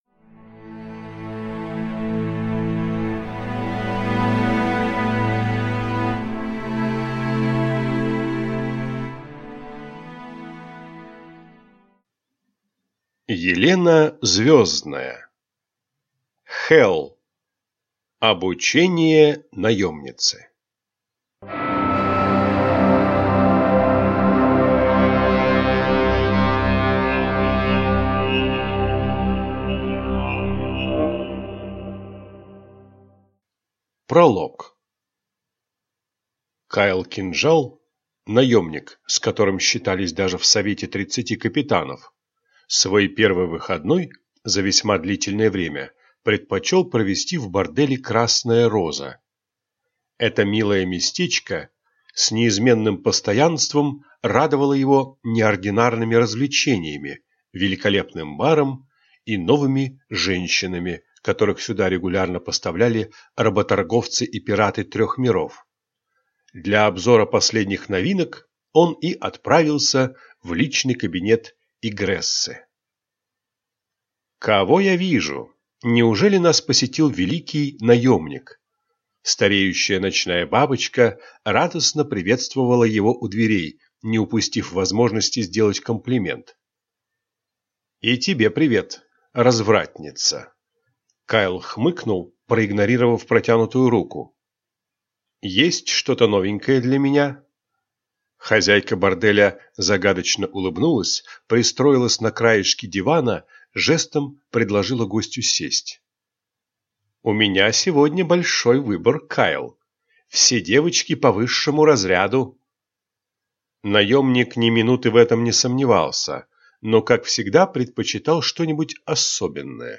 Аудиокнига Хелл. Обучение наемницы | Библиотека аудиокниг